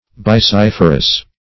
\Bys*sif"er*ous\